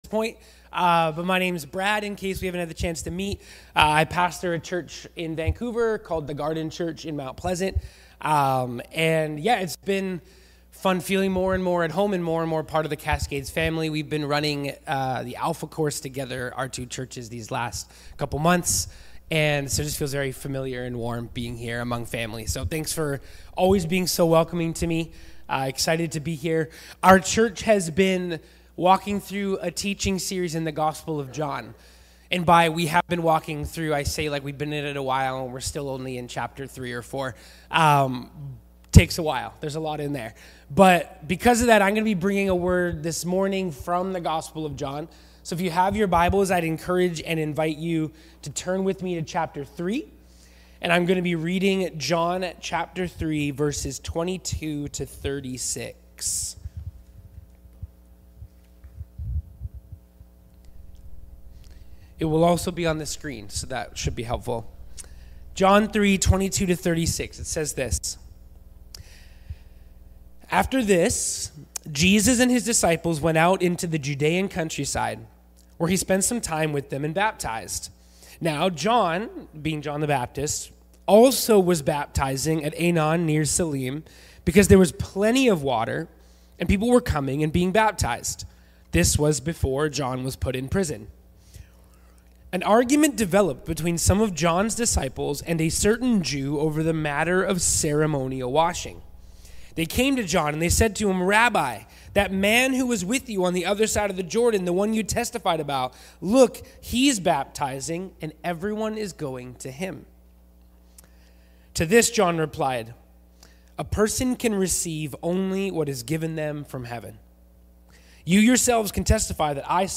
Cascades Church Sermons The Gospel Of John Play Episode Pause Episode Mute/Unmute Episode Rewind 10 Seconds 1x Fast Forward 30 seconds 00:00 / 40:05 Subscribe Share Apple Podcasts RSS Feed Share Link Embed